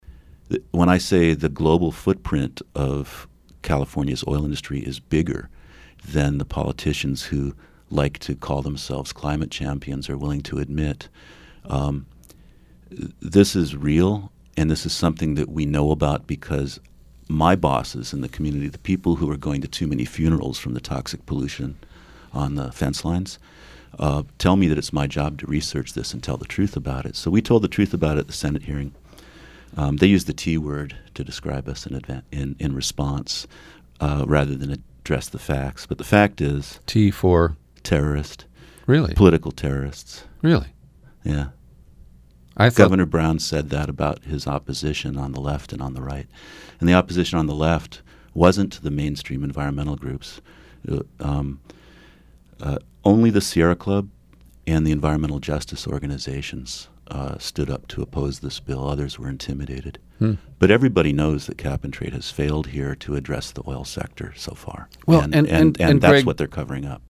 We begin the interview with the audio from a local TV news report on Gov. Brown’s signing of twin bills, staged on Treasure Island in San Francisco Bay and joined by his GOP predecessor, Arnold Schwarzenegger, who used the same scene 11 years ago to sign AB 32, which initiated a cap and trade program for carbon emitters.